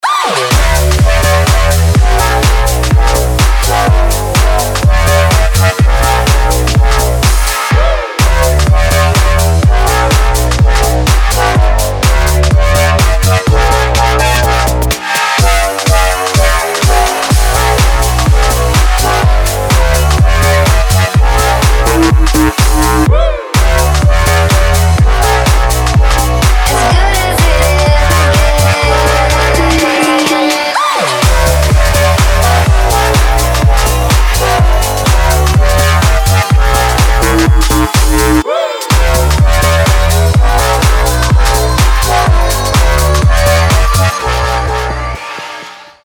• Качество: 320, Stereo
future house
Jackin House
Свежий танцевальный звучок